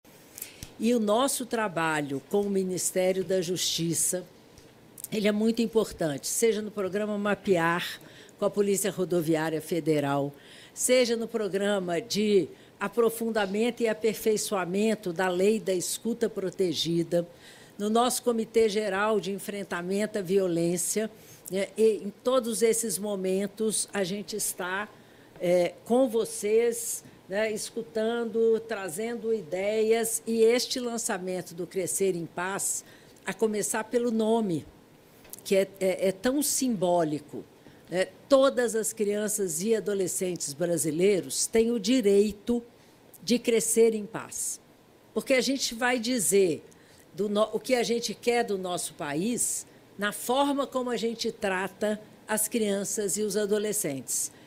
Pilar Lacerda, Secretária Nacional dos Direitos da Criança e Adolescente, fala sobre o trabalho em conjunto feito com o MJSP — Ministério da Justiça e Segurança Pública